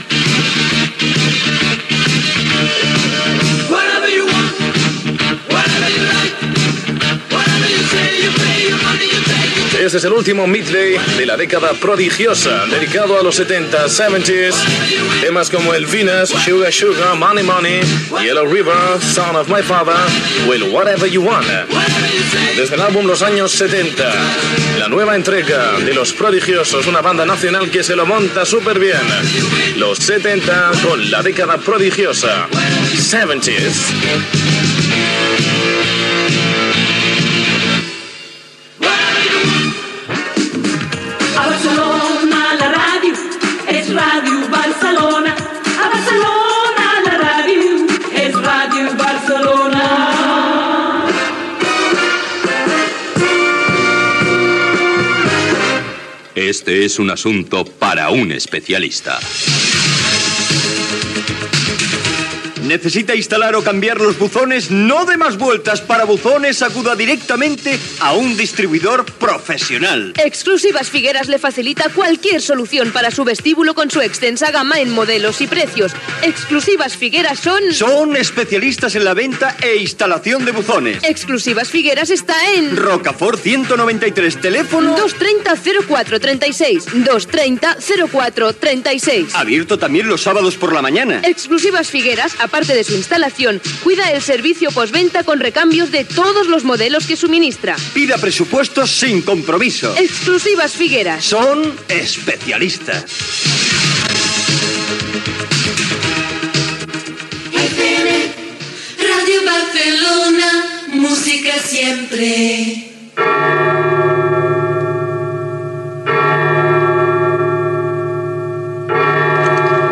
Tema musical, comentari sobre el tema que sona, indicatiu de la ràdio, publicitat, indicatiu, hora, "Bolero Mix 2", tema musical.
Musical